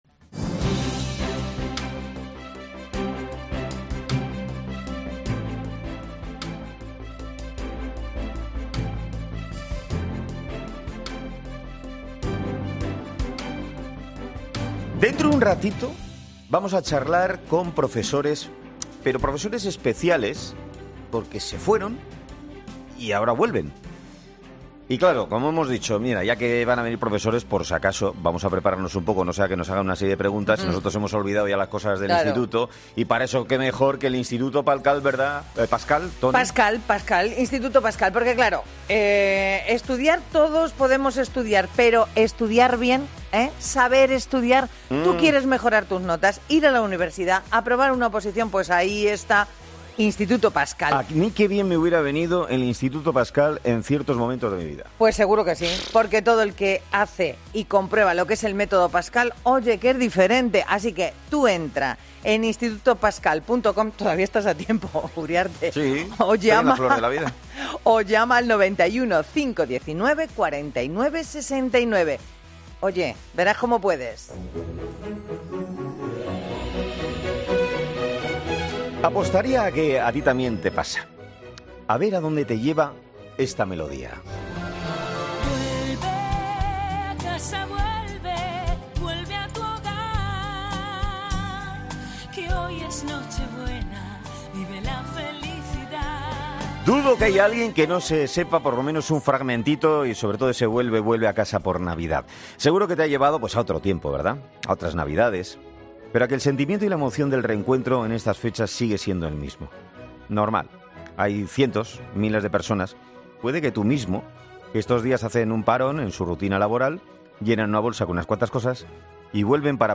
Presentado por Carlos Herrera, el comunicador mejor valorado y más seguido de la radio española, es un programa matinal que se emite en COPE, de lunes a viernes,...